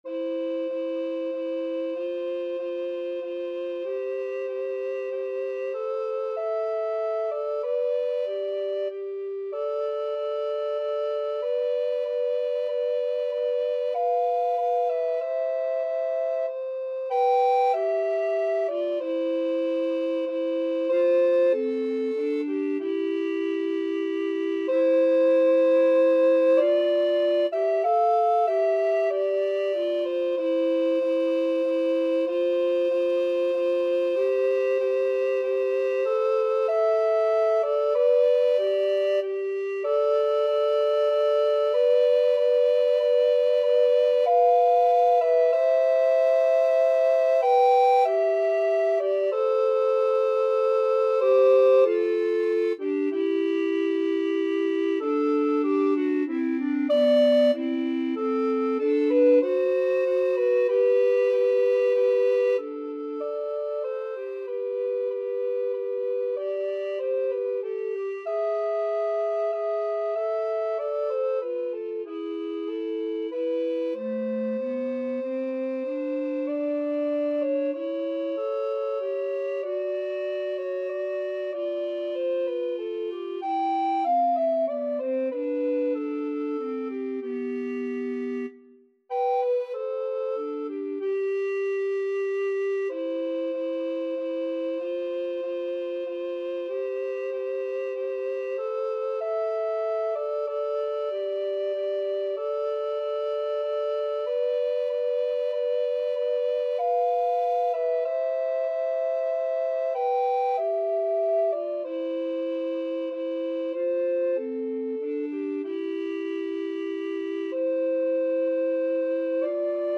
Tenor RecorderBass Recorder
=95 Andante
3/4 (View more 3/4 Music)
Classical (View more Classical Recorder Duet Music)